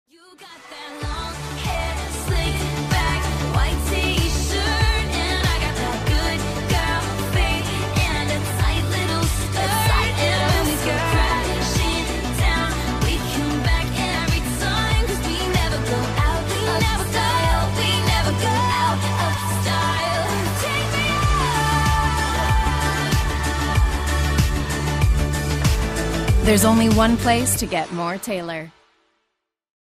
• Pop rock